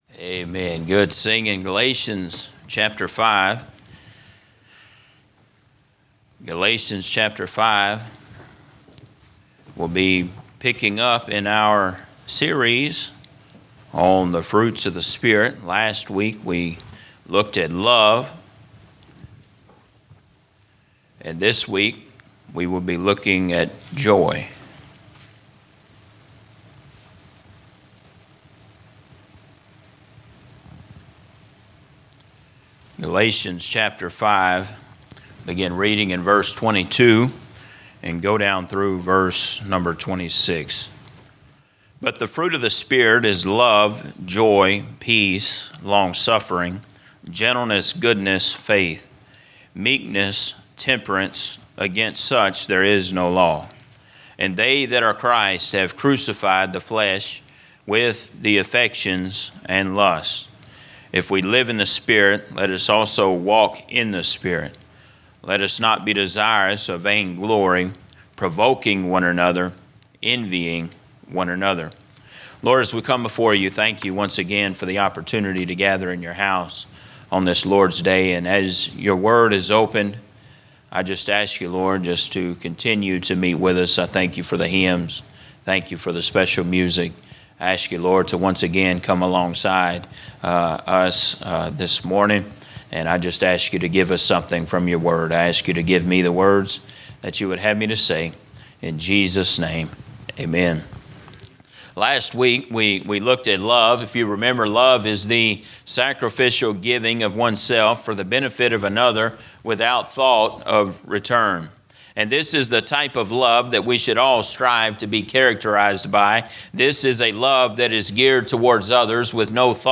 A message from the series "The Fruit of the Spirit." In a world that demands instant relief and quick escapes, the biblical concept of longsuffering often feels like a lost art.